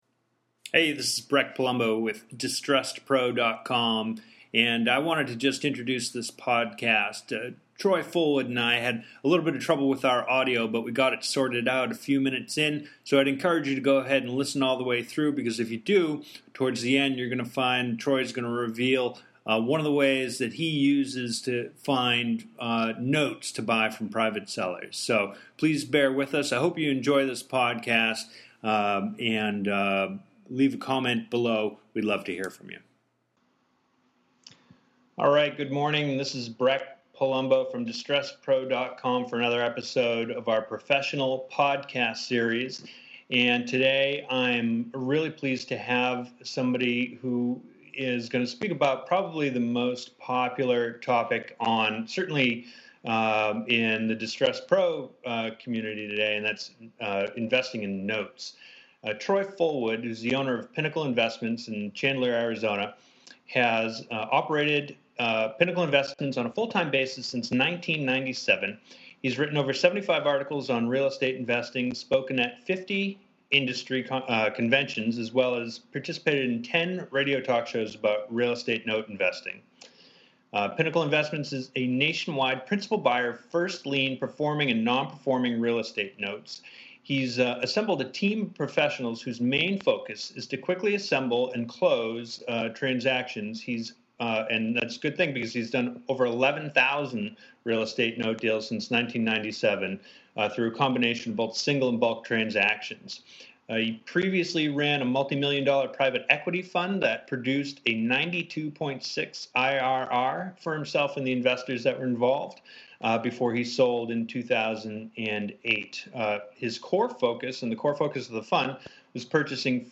I’m cranking up another set of excellent interviews and podcasts for the spring.